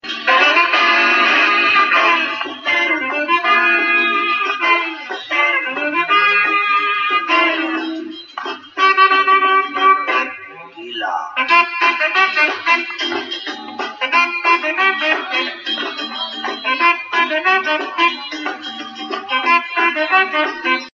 Jazz Ringtones